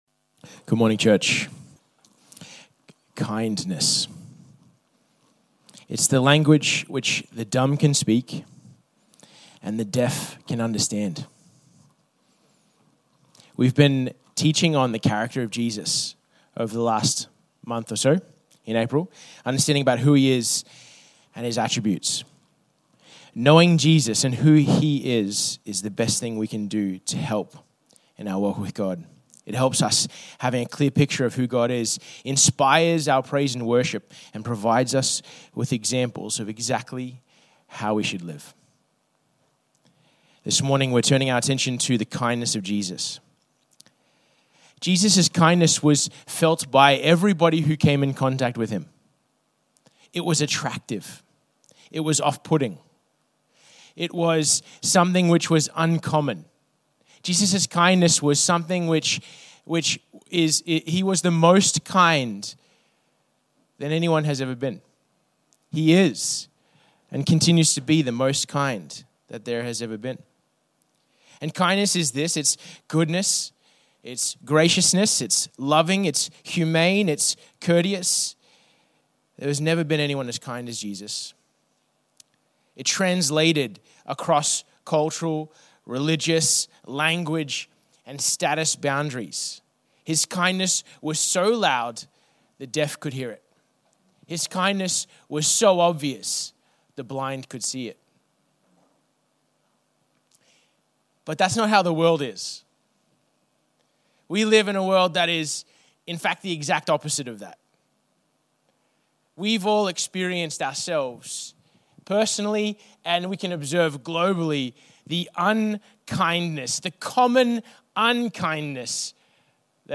Infinity Church Podcast - English Service | Infinity Church